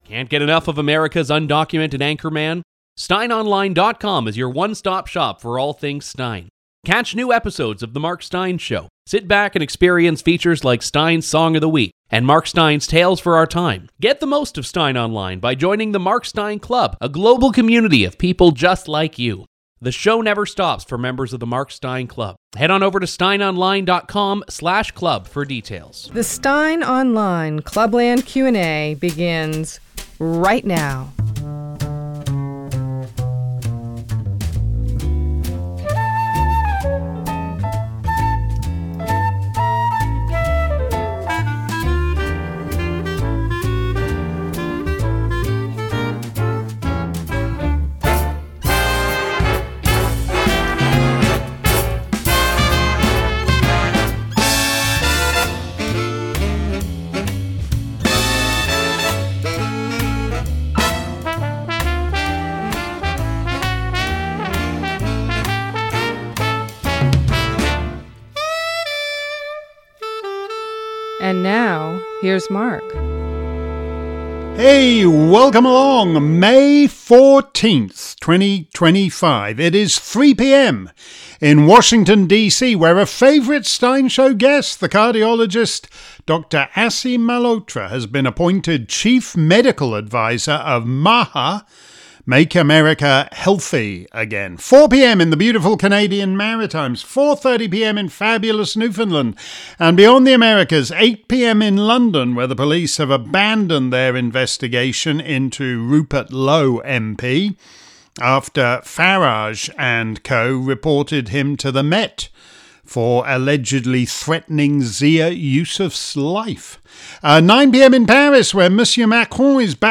If you missed today's edition of Steyn's Clubland Q&A live around the planet, here's the action replay. This week's show covered a range of topics from Trump in the Middle East to Keir Starmer going full Enoch.